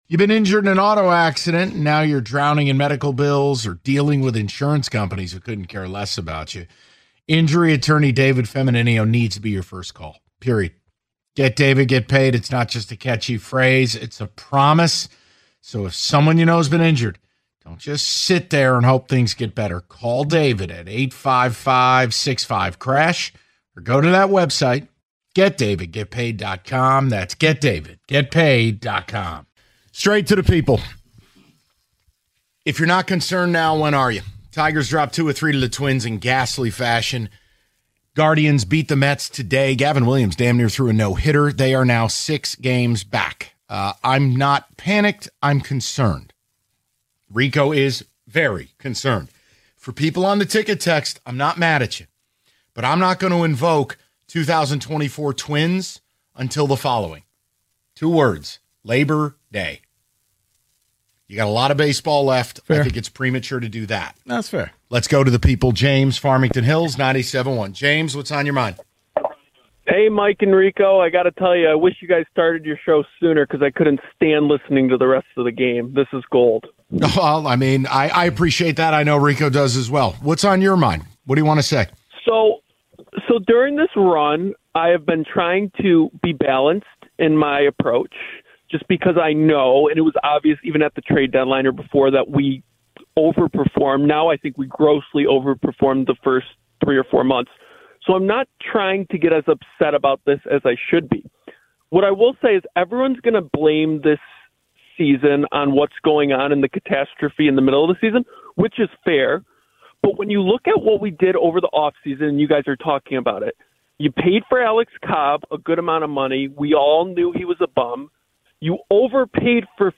The Callers Share Their Frustration With The Tigers